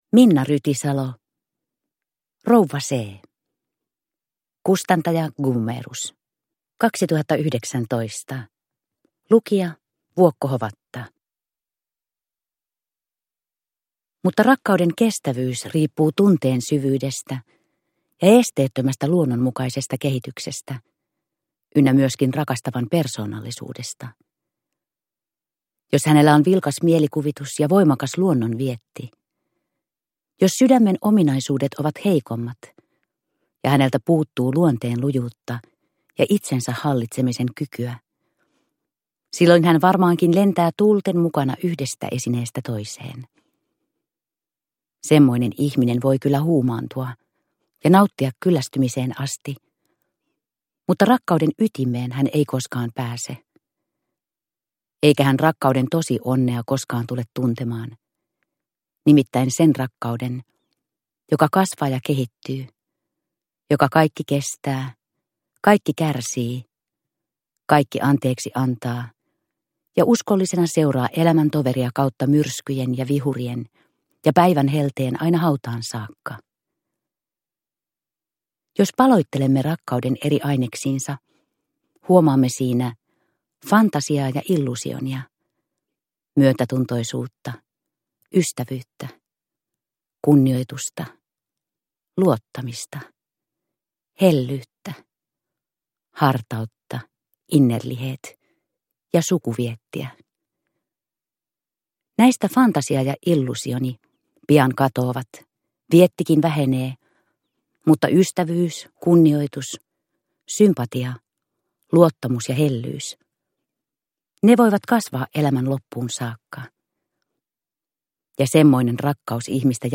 Rouva C. – Ljudbok – Laddas ner